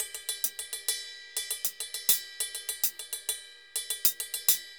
Ride_Baion 100_1.wav